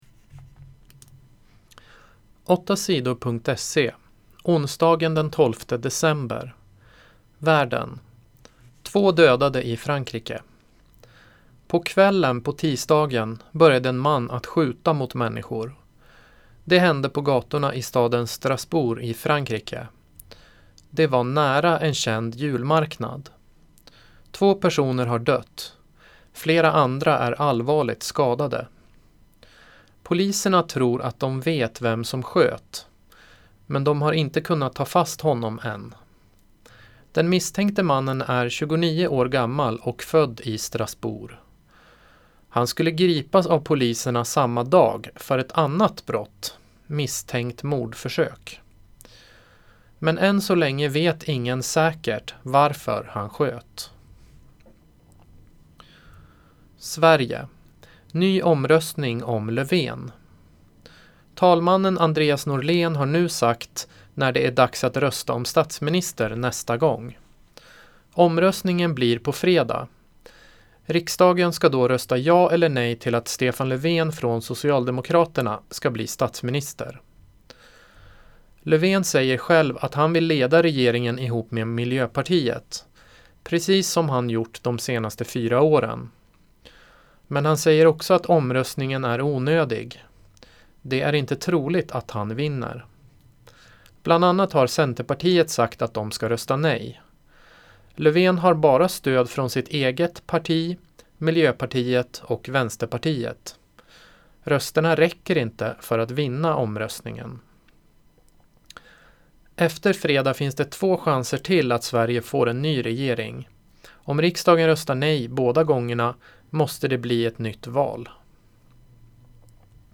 8 Sidor gör nyheter på lätt svenska.